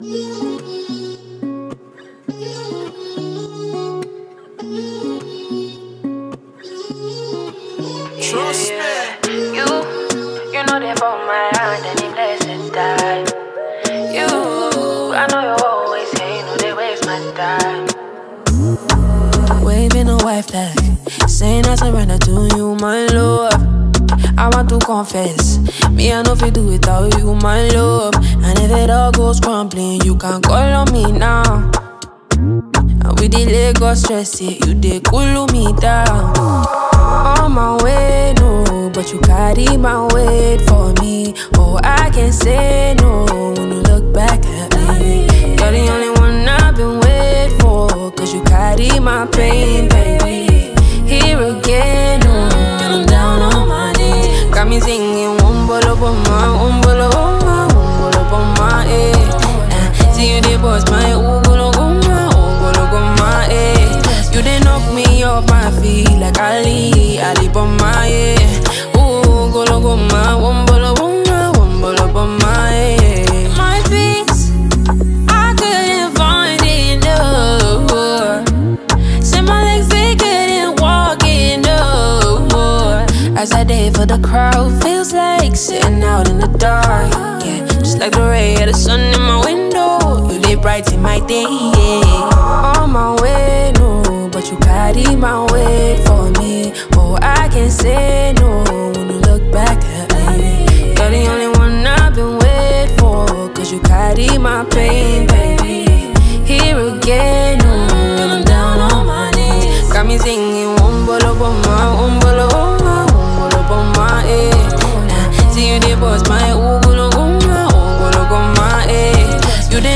Nigeria talented Afrobeats music singer and songwriter